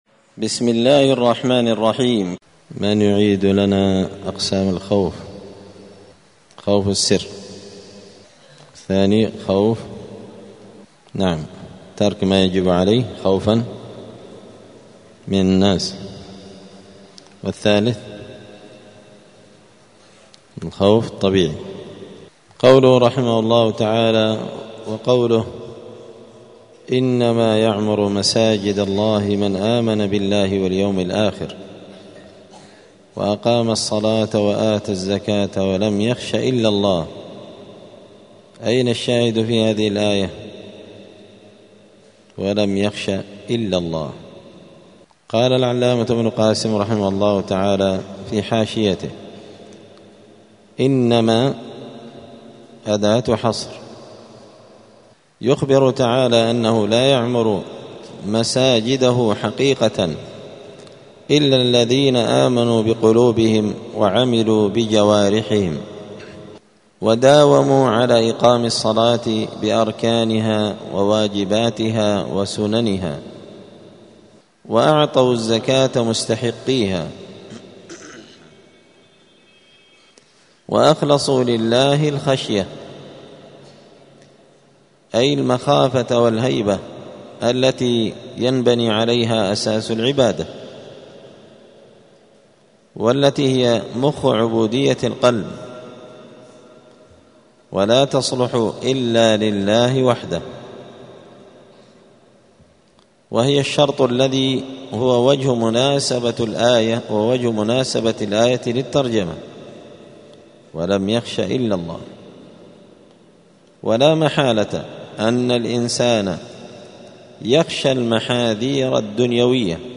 دار الحديث السلفية بمسجد الفرقان قشن المهرة اليمن
*الدرس التسعون (90) {تابع لباب قول الله تعالى إنما ذلكم الشيطان يخوف أولياءه}*